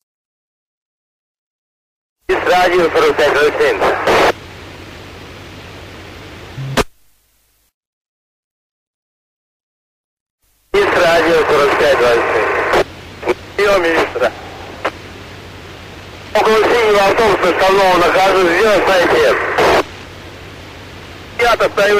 Звуки рации
Звук рации российской дорожной полиции